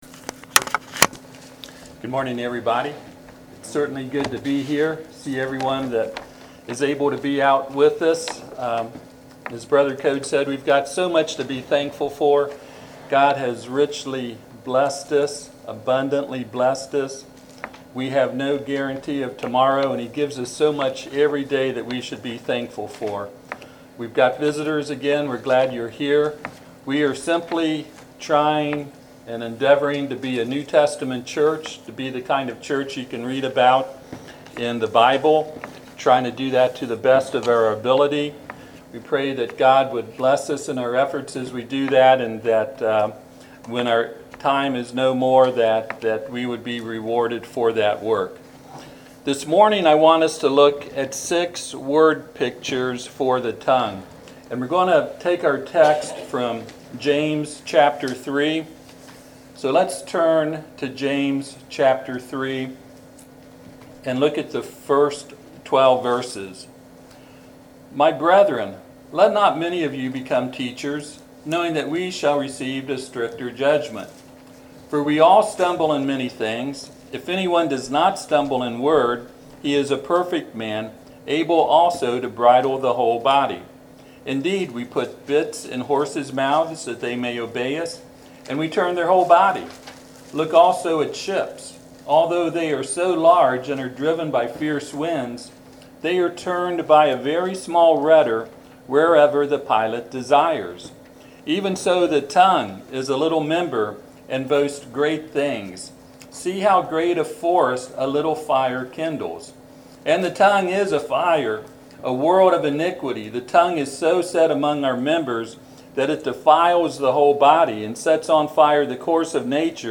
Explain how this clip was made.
Passage: Jamse 3:1-12 Service Type: Sunday AM Topics